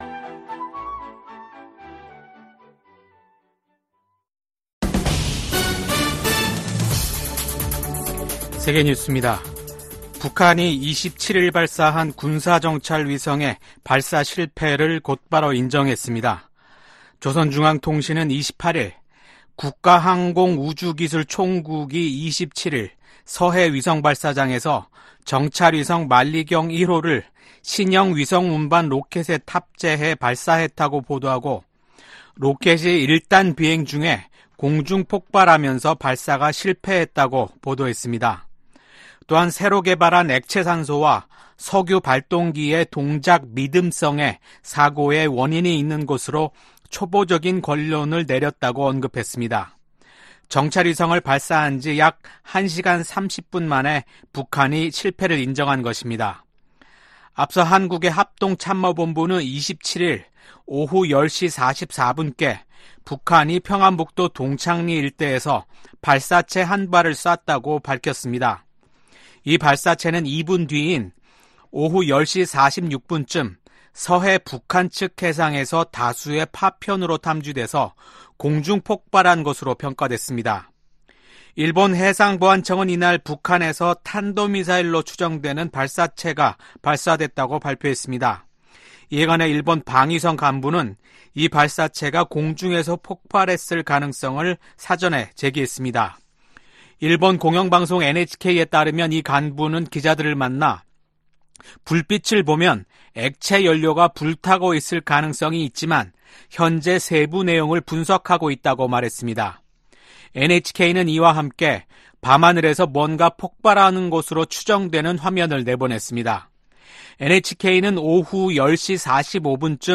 VOA 한국어 아침 뉴스 프로그램 '워싱턴 뉴스 광장' 2024년 5월 28일 방송입니다. 한국과 일본, 중국 세 나라 서울에서 정상회의를 열고 북한 문제를 비롯한 3국 현안을 논의했습니다. 일본 항공국이 북한의 위성 발사가 예상된다며 자국 인근 상공을 지나는 항공기에 주의를 당부하는 항공고시보를 발행했습니다.